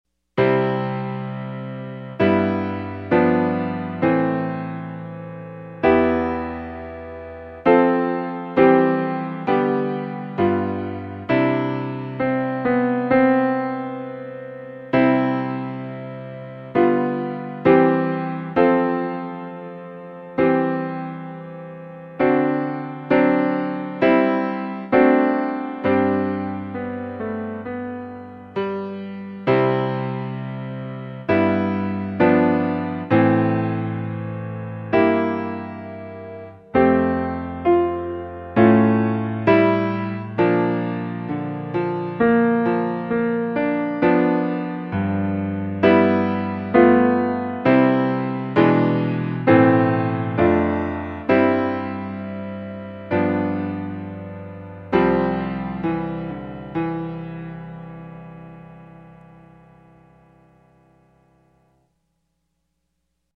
Piano Solo - Early Intermediate